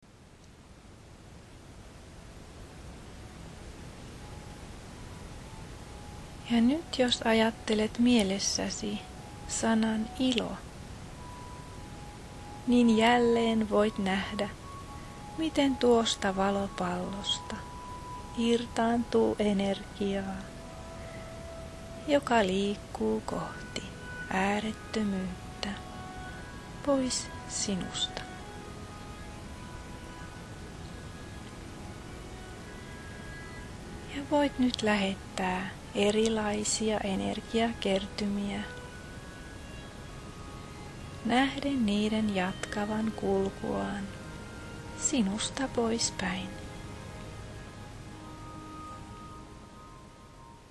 Alkulähteen meditaatio, kesto 13,56 min
Alkulahteen meditaatio nayte.mp3